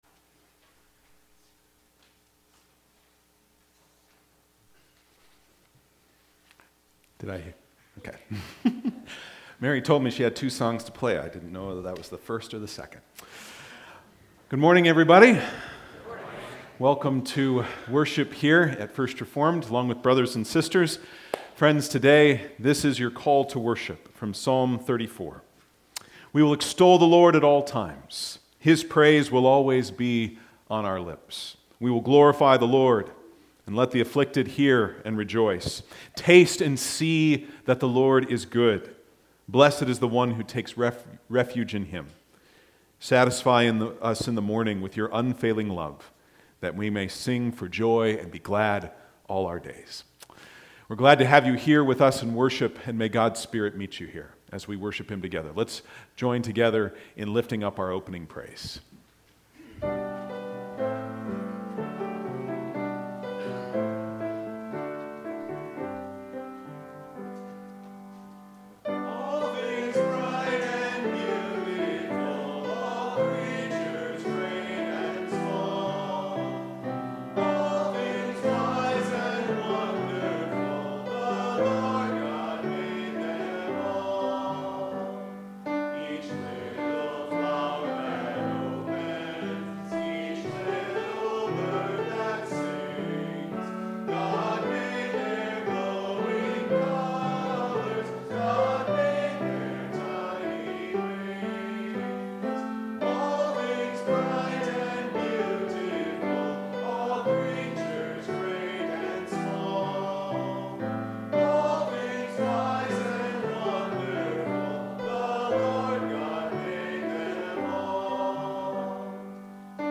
Sermons | Randolph First Reformed Church